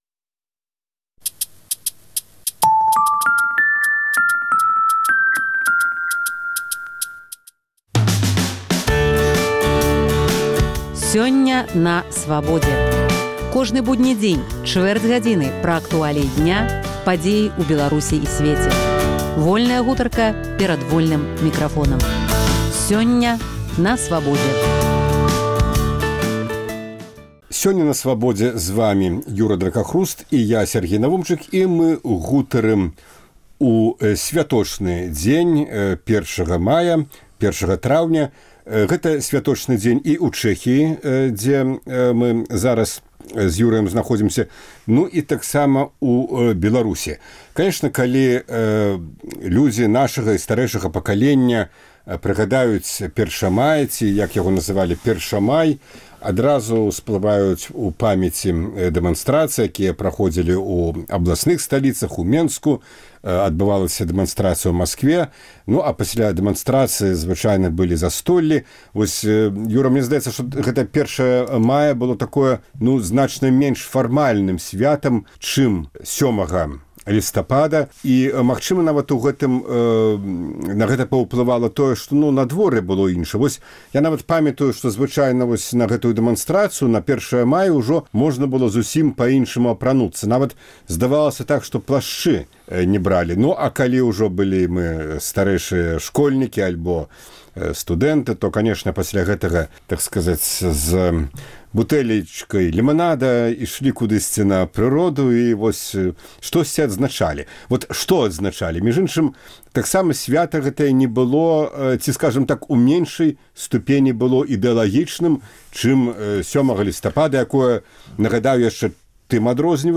Гутарка на тэмы дня